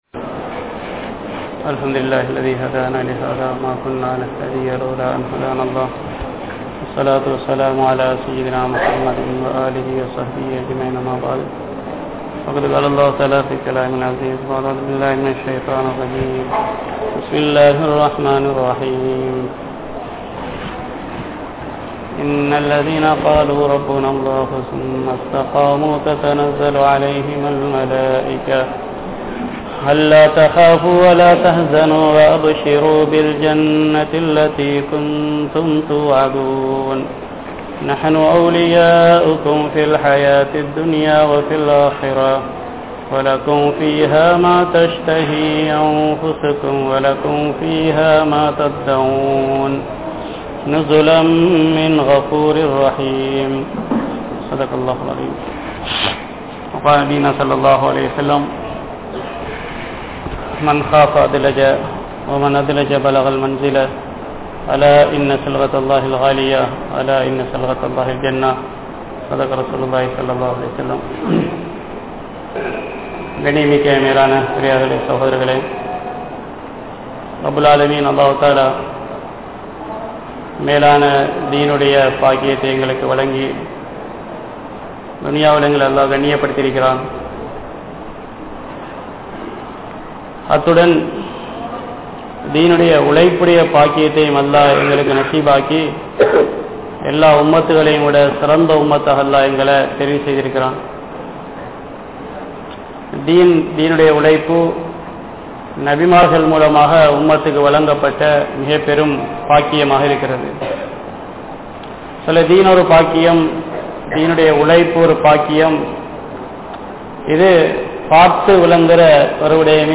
Dheen Entraal Enna? (தீன் என்றால் என்ன?) | Audio Bayans | All Ceylon Muslim Youth Community | Addalaichenai